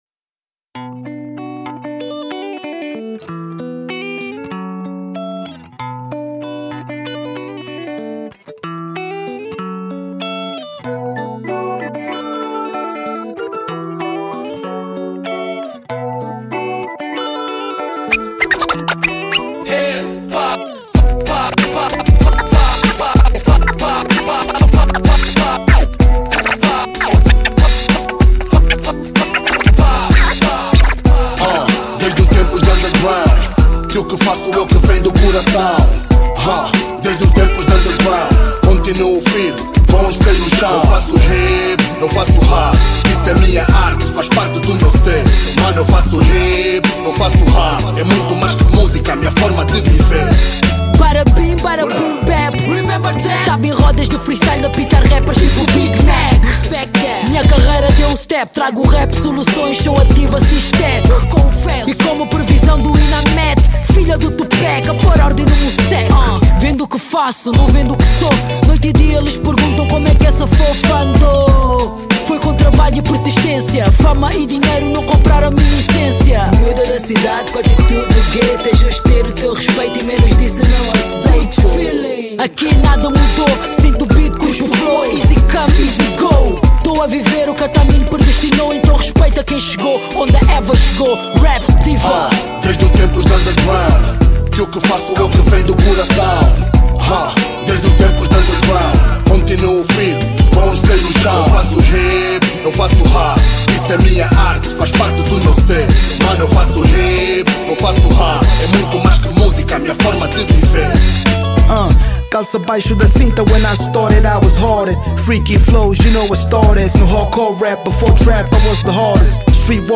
Género: Hip-ho /Rap